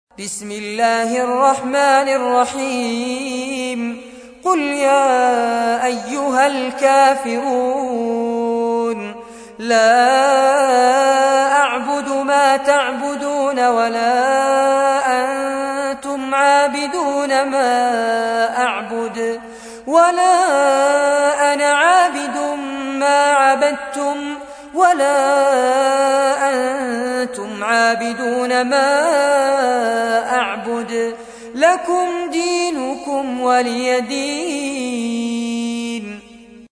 تحميل : 109. سورة الكافرون / القارئ فارس عباد / القرآن الكريم / موقع يا حسين